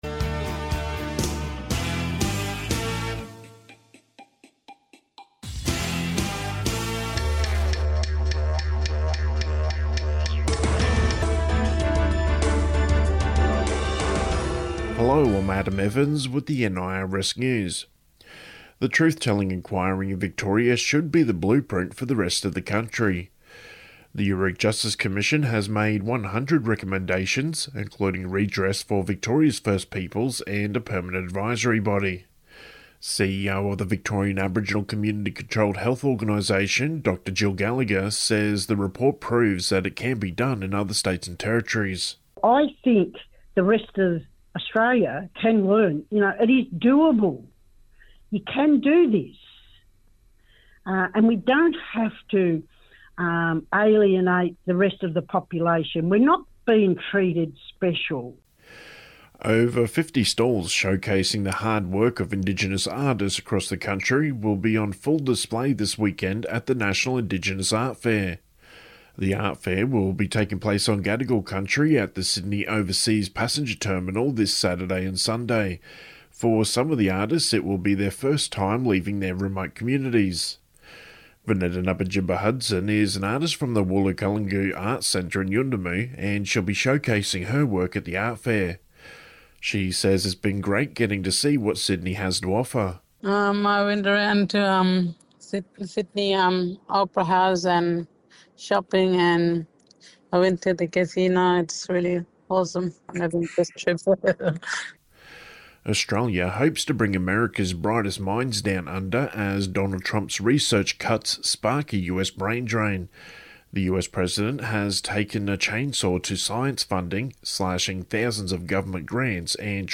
The BBM Team yarning with deadly mob from the community and stalls at Fogarty Park.